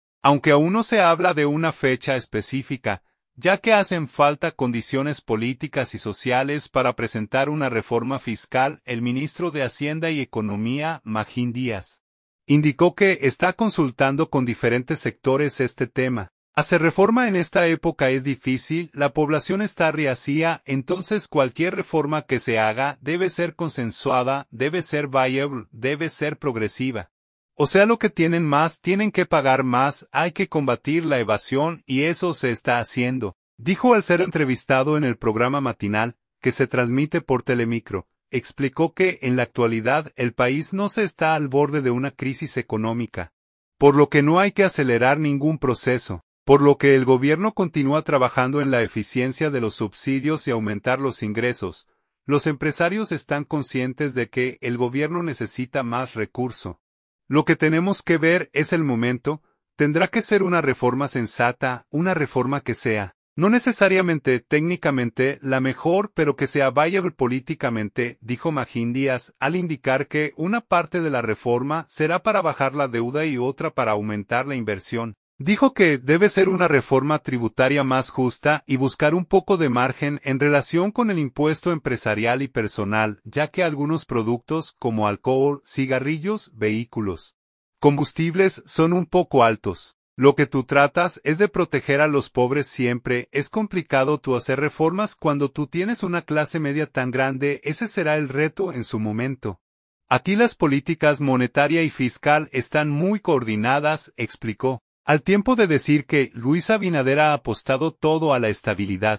“Hacer reforma en esta época es difícil, la población está rehacía, entonces cualquier reforma que se haga debe ser consensuada, debe ser viable, debe ser progresiva, o sea lo que tienen más tienen que pagar más, hay que combatir la evasión y eso se está haciendo”, dijo al ser entrevistado en el programa Matinal, que se transmite por Telemicro.